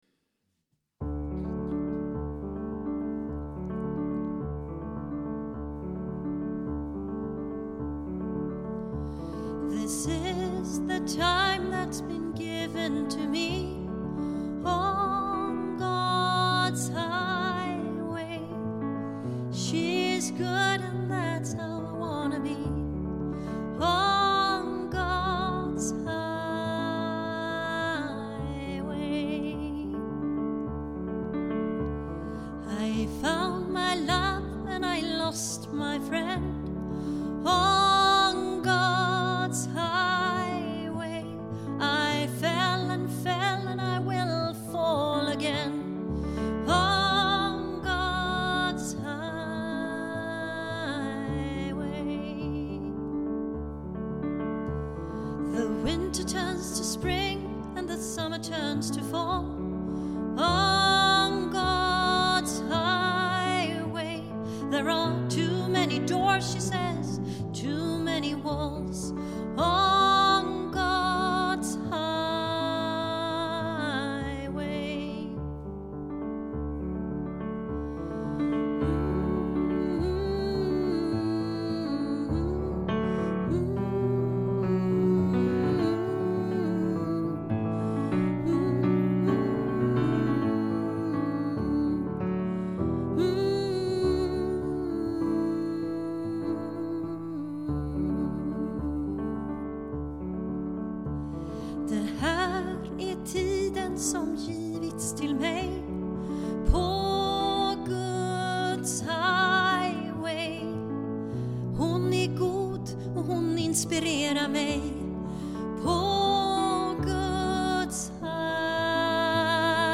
RÖST
Sång-3.mp3